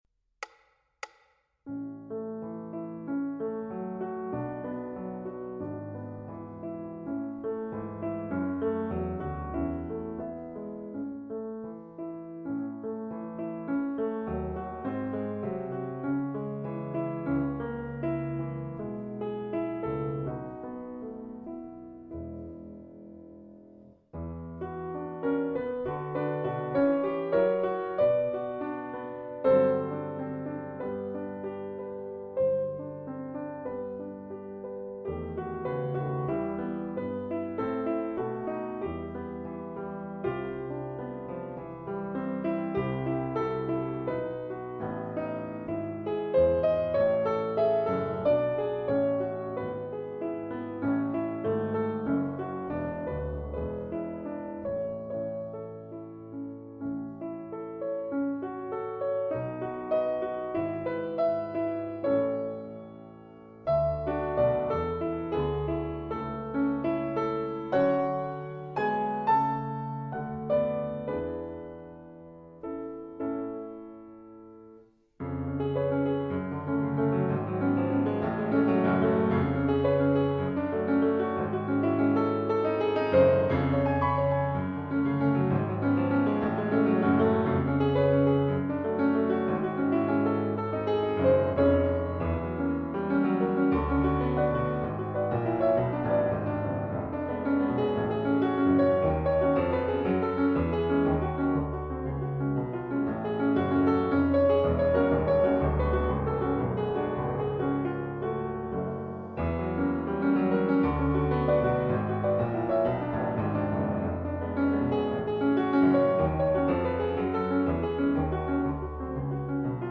Romance no 2 Backing Track